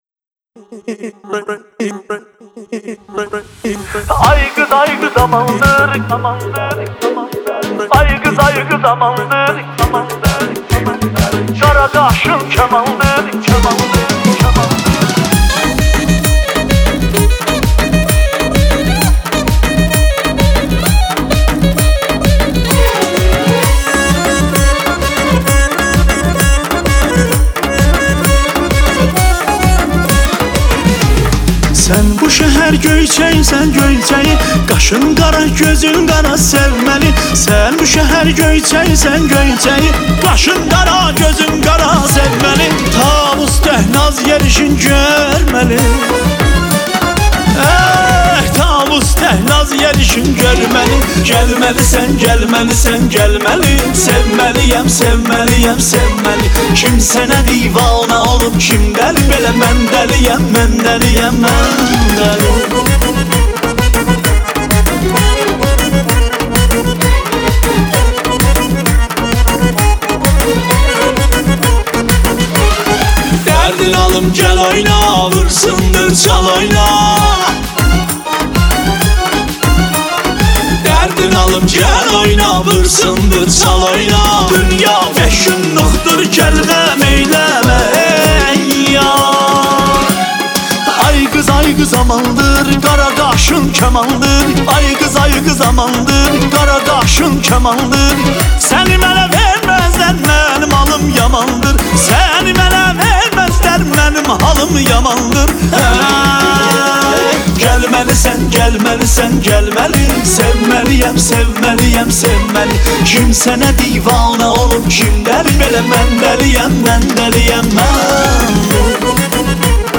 آهنگ و ریمیکس شاد آذری و ترکی گلچین یکجا